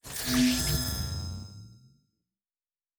pgs/Assets/Audio/Sci-Fi Sounds/Electric/Shield Device 1 Start.wav at master
Shield Device 1 Start.wav